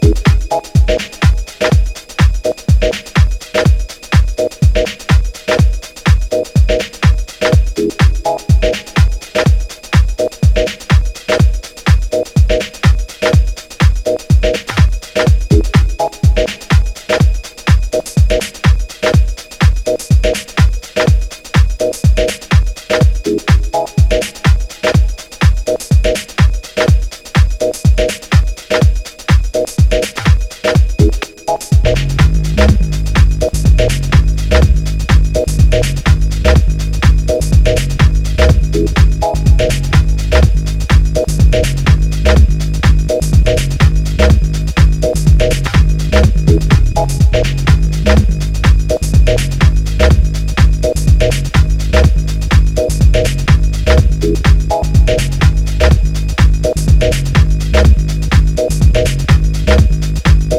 【12"INCH】(レコード・限定500枚プレス)
ジャンル(スタイル) DEEP HOUSE